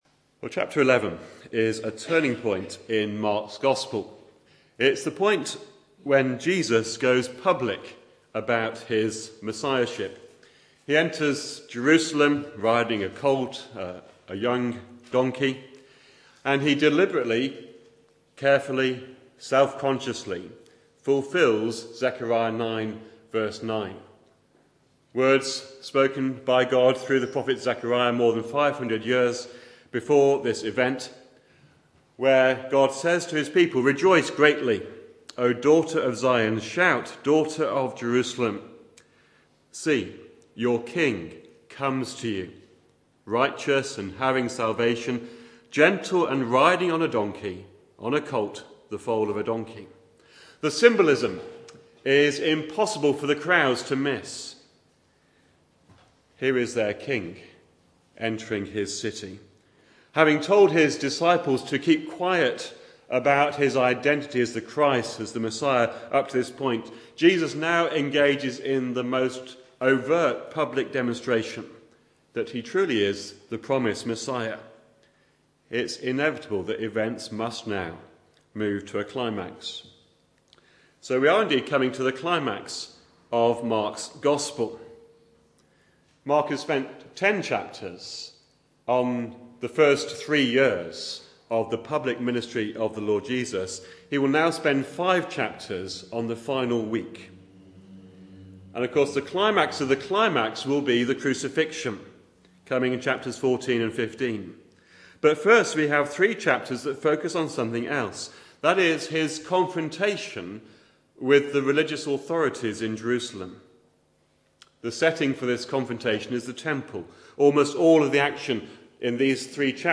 An independent evangelical church
Back to Sermons The King comes to His temple